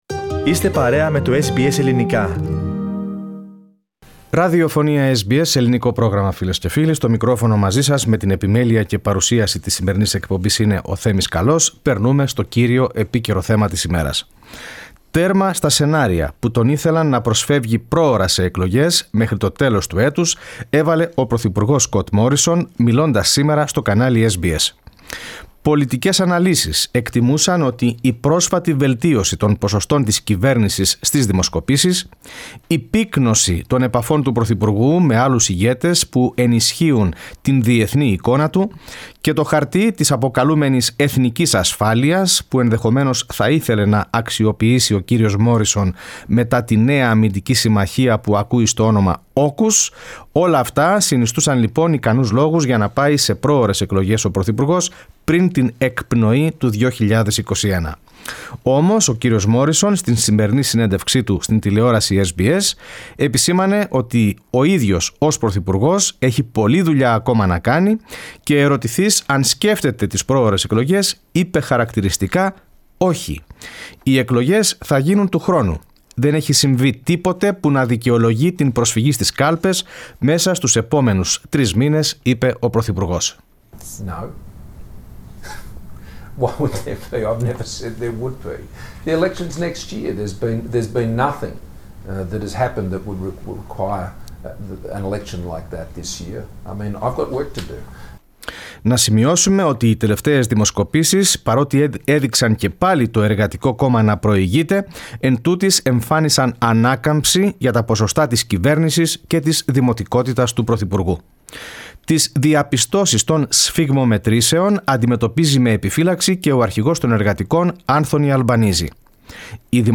Prime Minister Scott Morrison has ruled out calling an early election in an interview with SBS News in Washington.